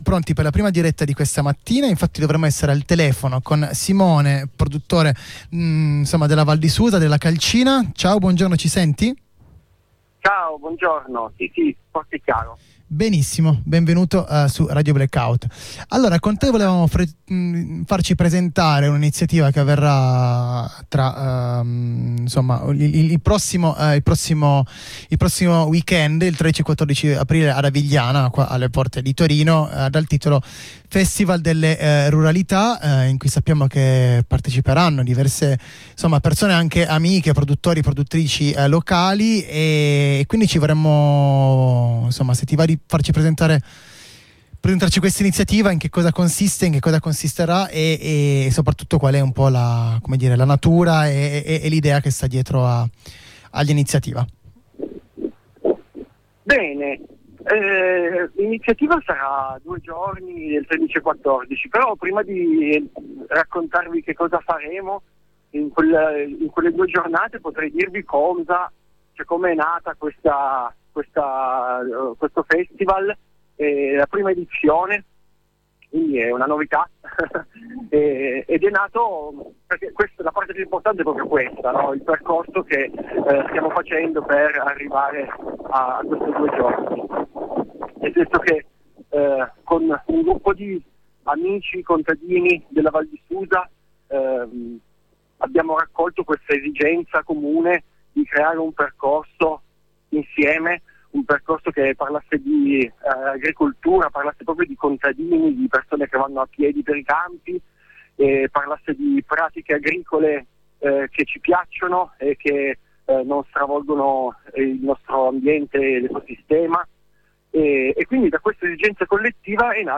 Ascolta la diretta ai microfoni di Radio Blackout: